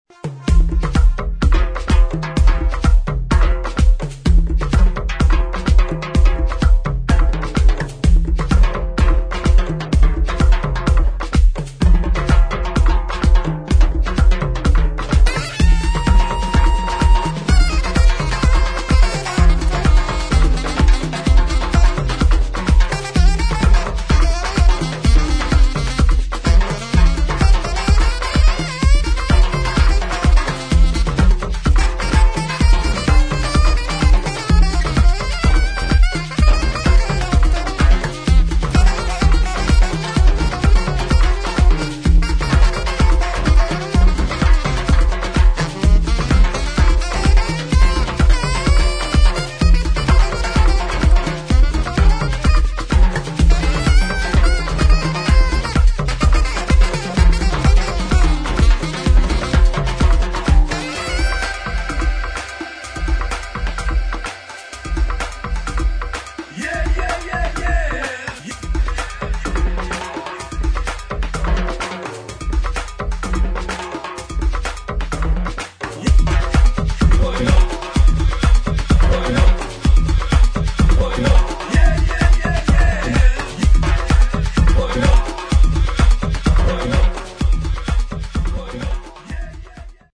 [ HOUSE | TECH HOUSE ]